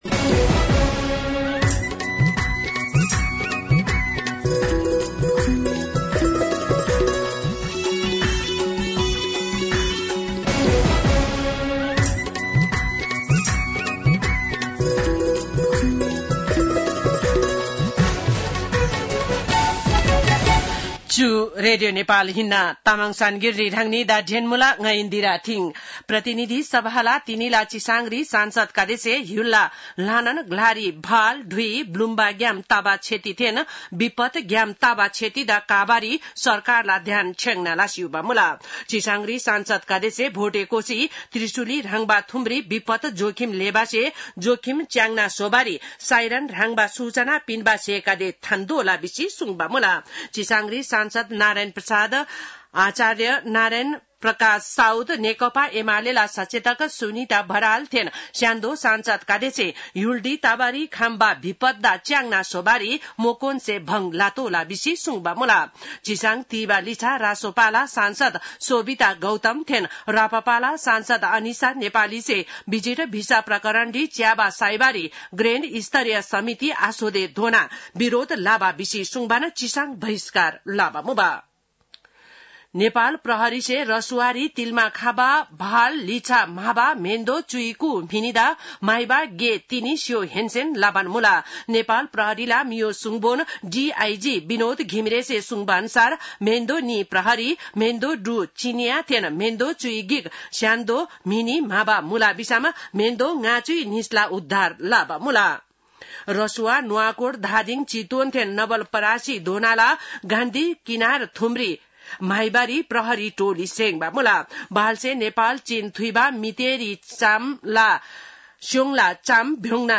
तामाङ भाषाको समाचार : २५ असार , २०८२